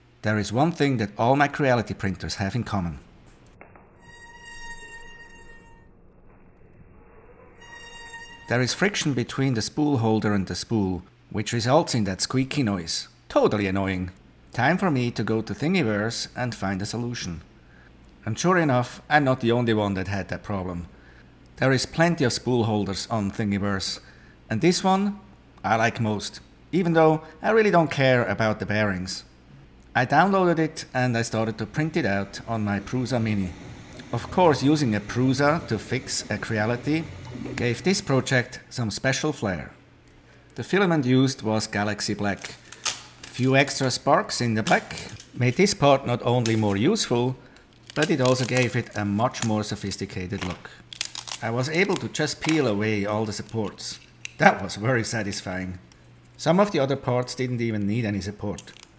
Creality 3D printer squeaky noise